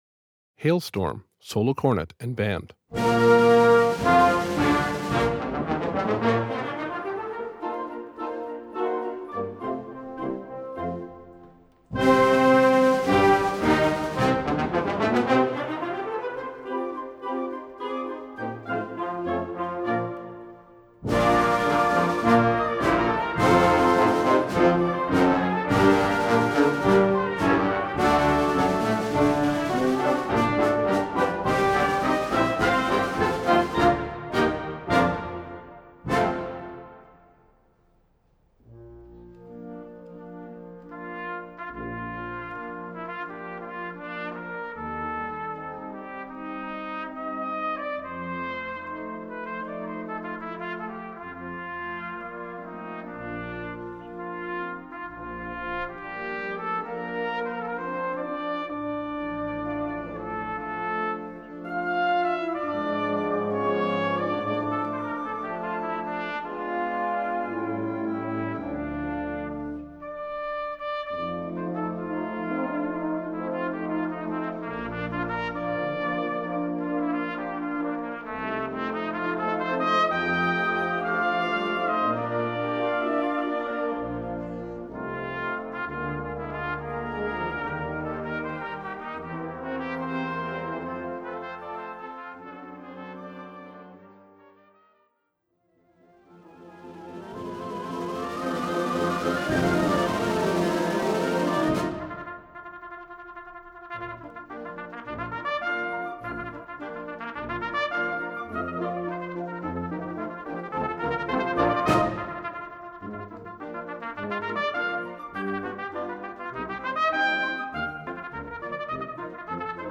Voicing: Cornet w/ Band